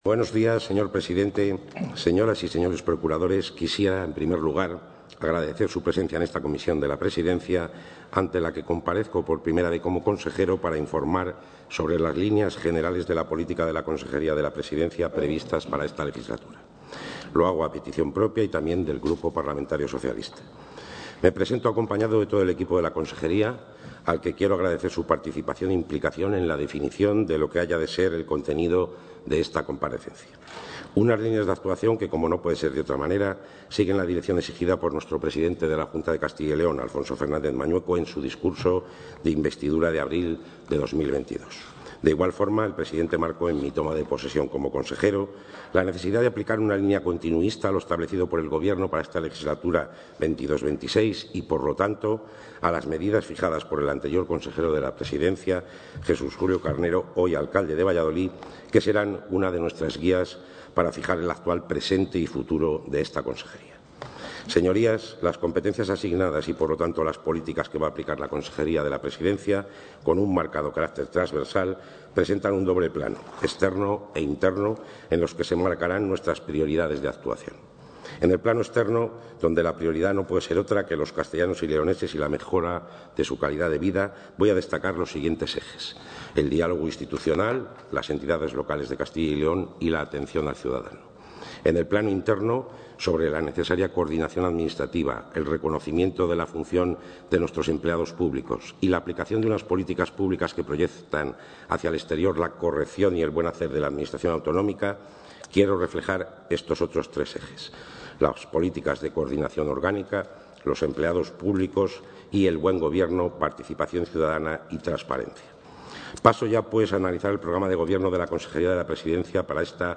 El consejero de la Presidencia, Luis Miguel González Gago, ha presentado en las Cortes su programa de actuaciones a desarrollar en esta...
Intervención del consejero.